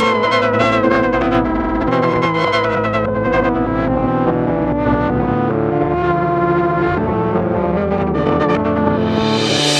LOOP - JUMBLINA.wav